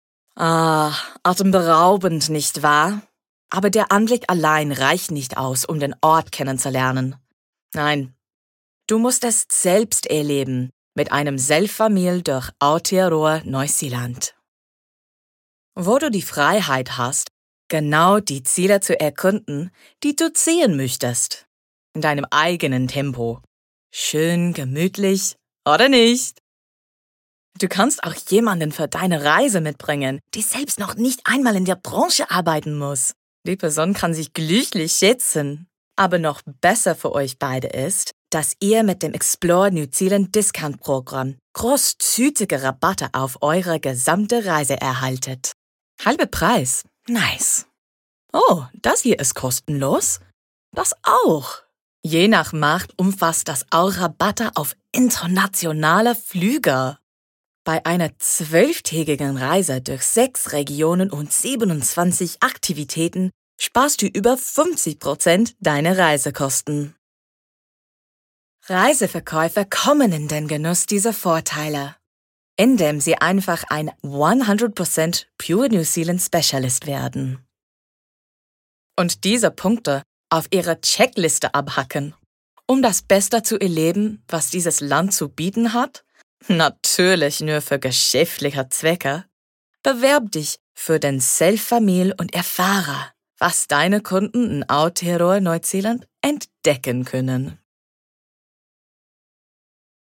Her sound is very easy on the Ear.
Tourism_NZ_Explore_German_VO_Master.mp3